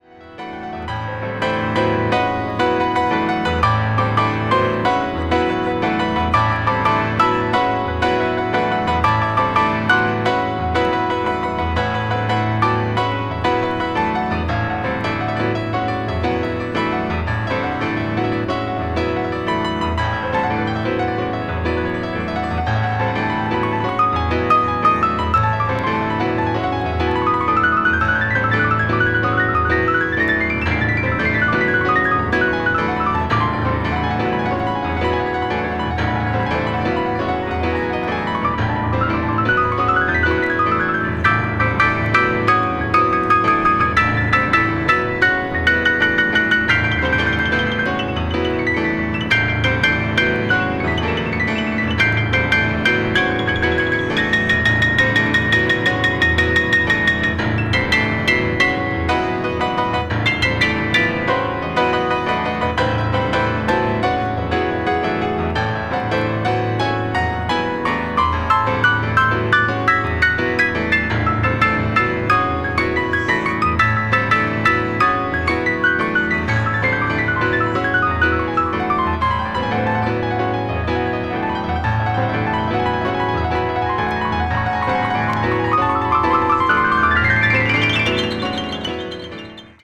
アコースティック・ピアノのソロ作品ですが、あなどるなかれ、物凄いことになってます。
星屑が降り注ぐかのように紡ぎだされるオーガニックな旋律があまりにも素晴らしいです。